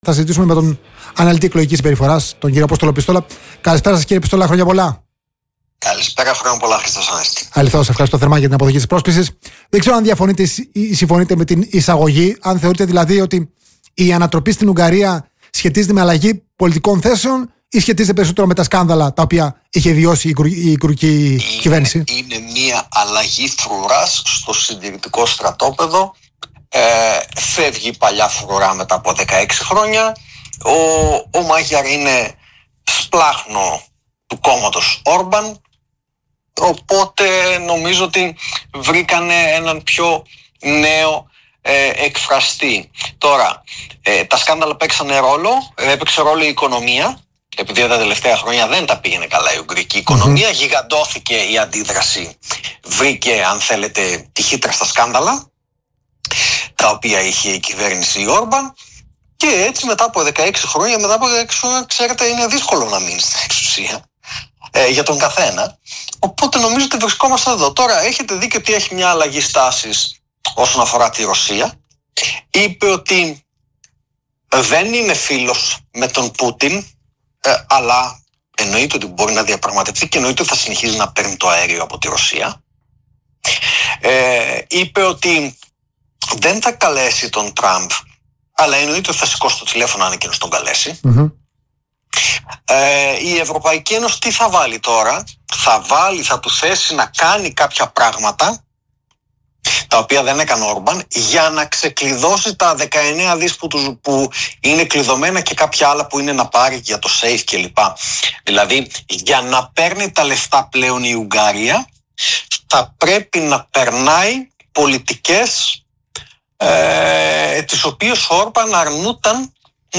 Στην εκπομπή «Καθαρά Πολιτικά» του Politica 89.8